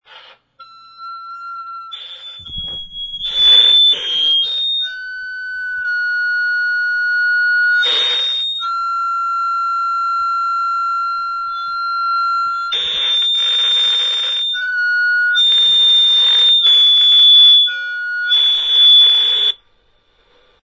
feedback noise.
If the transmitter is working, you will hear a feedback noise.
feeback-signal.mp3